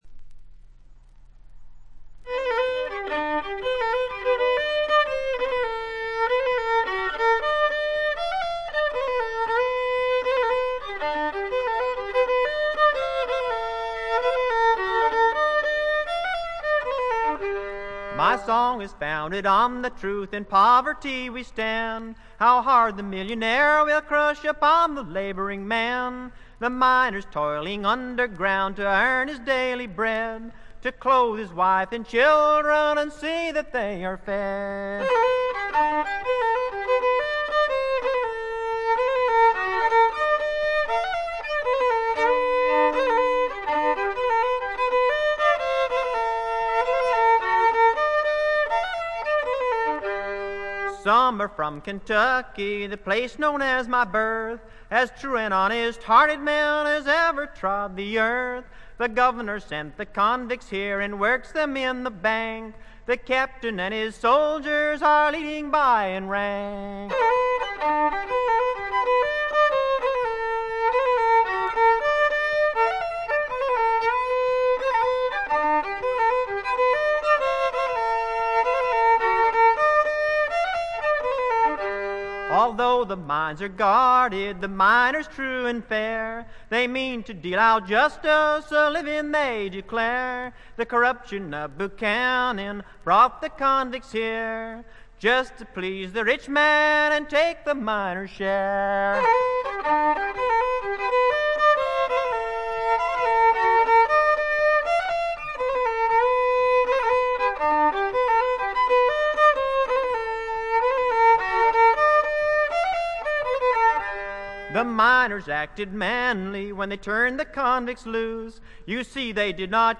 原初のブルーグラスの哀愁味あふれる歌が素晴らしいです！
試聴曲は現品からの取り込み音源です。